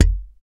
Index of /90_sSampleCDs/Roland L-CD701/BS _Jazz Bass/BS _E.Bass FX